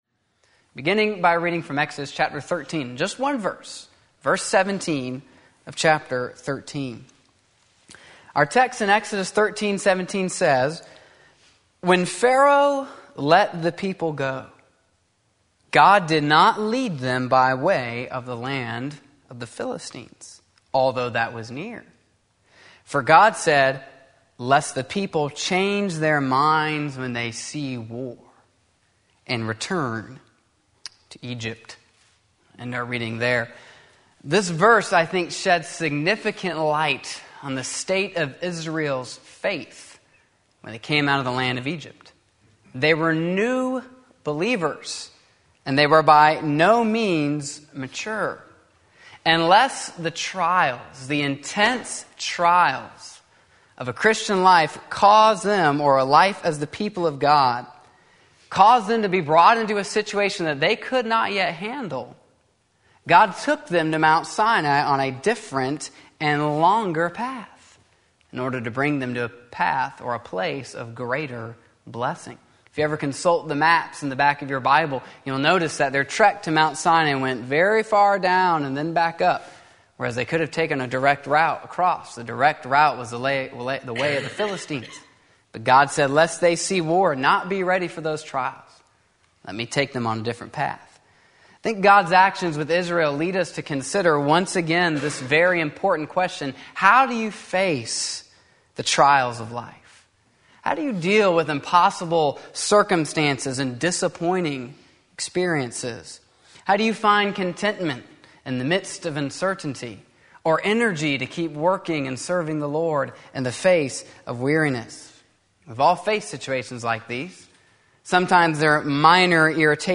Sermon Link
Part 2 Exodus 13-17 Sunday Afternoon Service